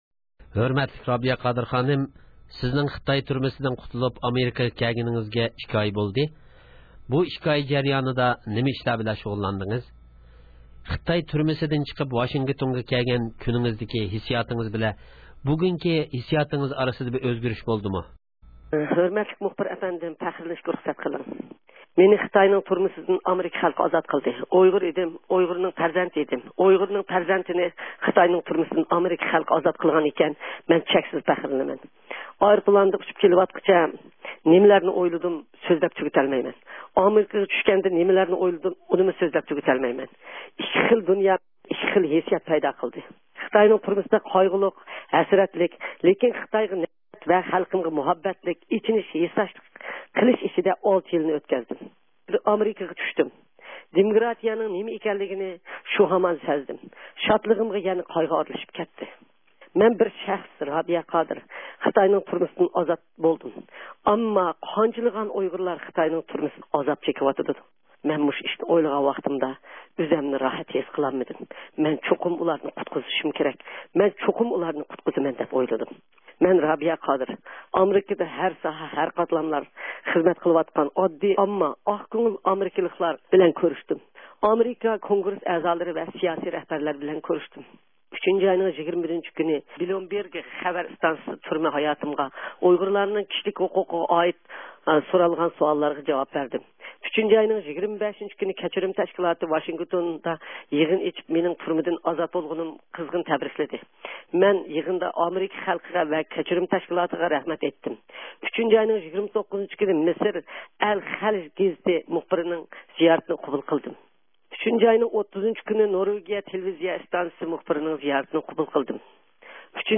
رابىيە قادىر خانىم بىلەن سۆھبەت – ئۇيغۇر مىللى ھەركىتى